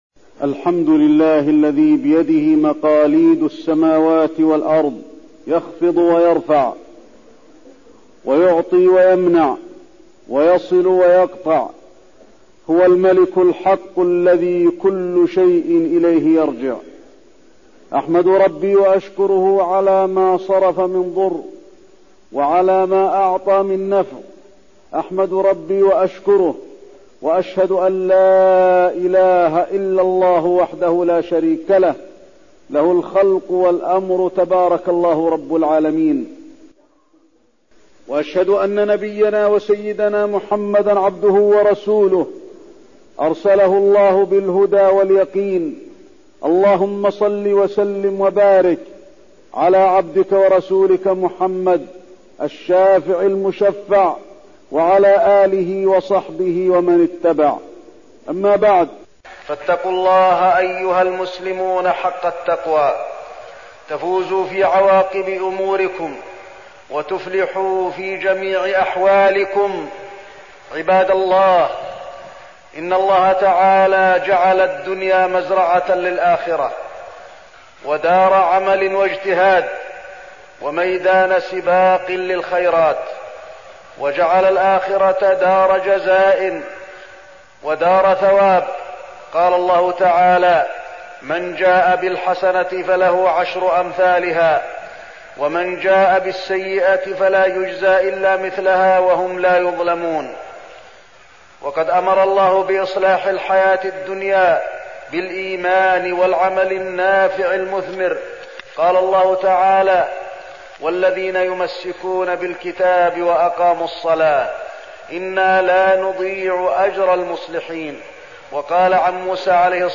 تاريخ النشر ٧ ربيع الأول ١٤١٨ هـ المكان: المسجد النبوي الشيخ: فضيلة الشيخ د. علي بن عبدالرحمن الحذيفي فضيلة الشيخ د. علي بن عبدالرحمن الحذيفي الأعمال النافعة